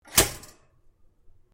Звуки тостера
звук тостера с заправленным хлебом